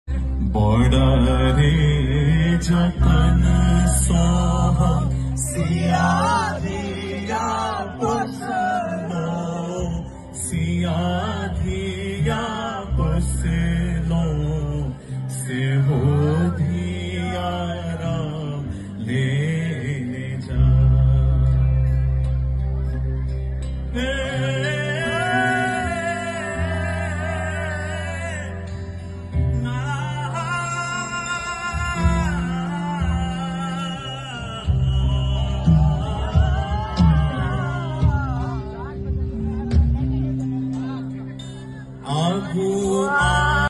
at Bhoomi Folk festival